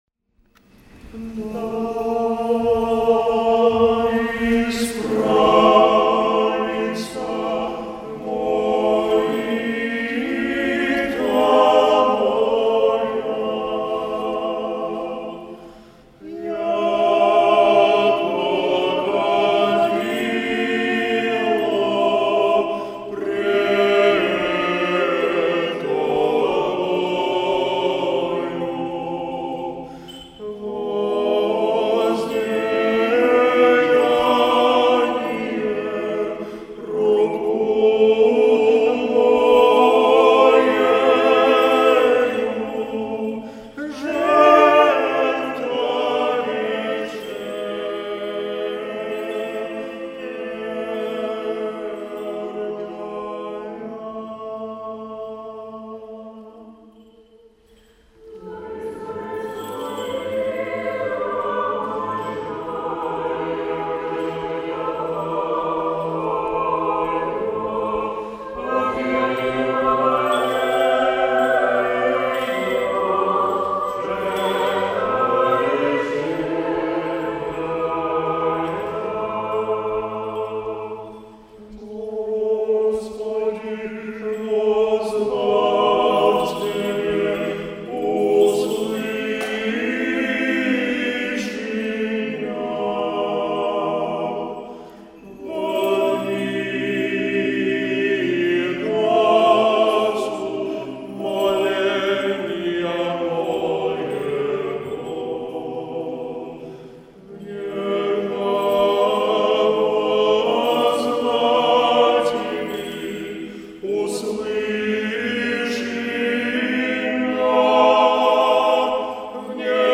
В пятницу первой седмицы Великого поста митрополит Игнатий совершил Литургию Преждеосвященных Даров в Воскресенском кафедральном соборе
исполнил хор духовенства Вологодской епархии
Архиерейский хор кафедрального собора